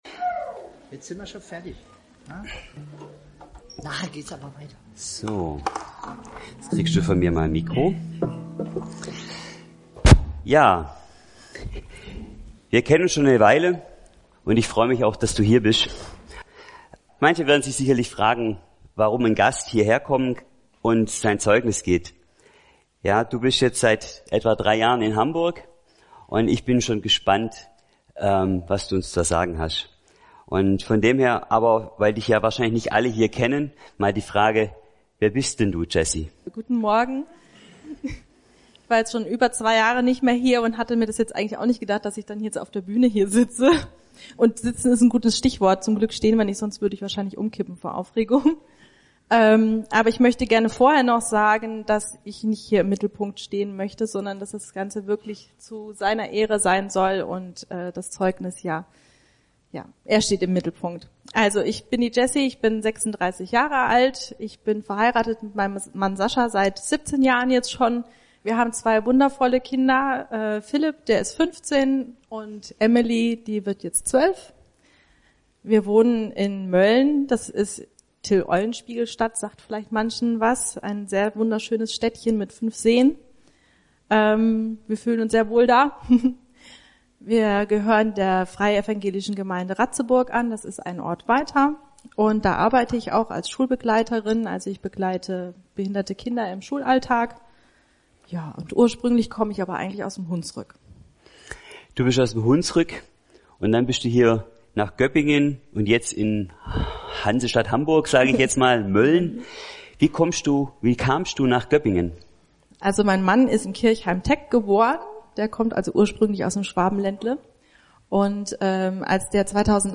Interview
Predigt